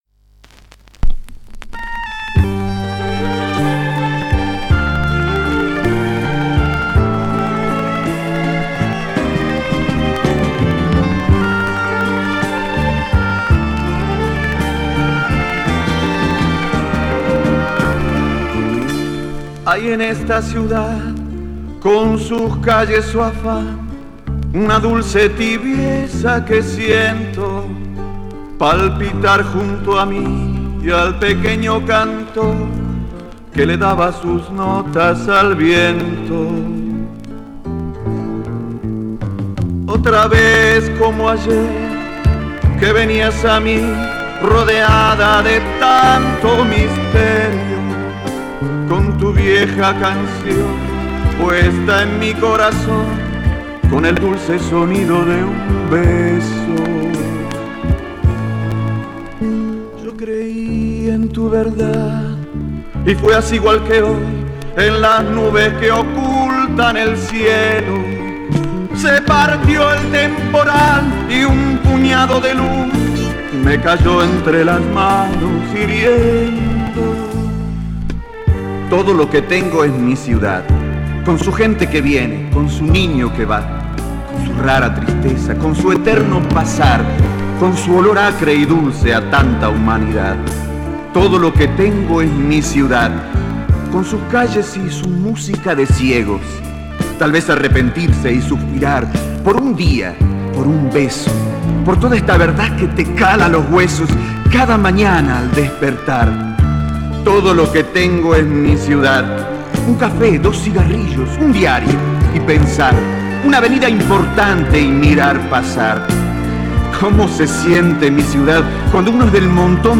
Actor y Cantante.